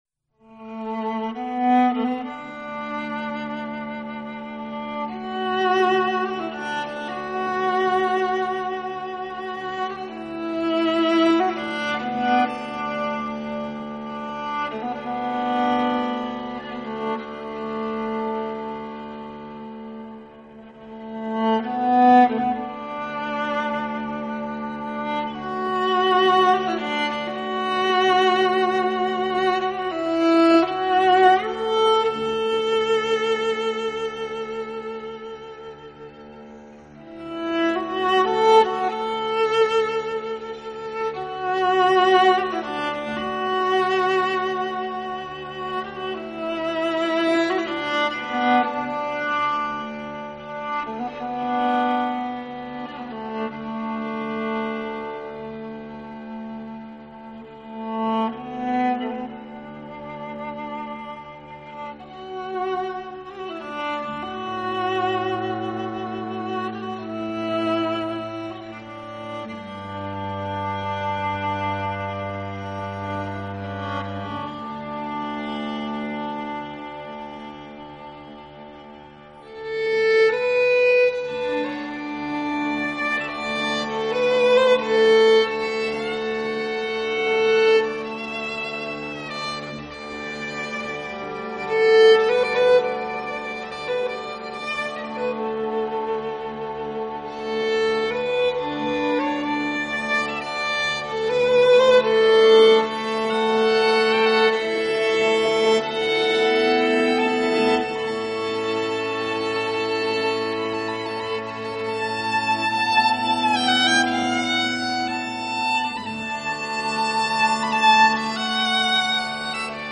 Genre：World, New Age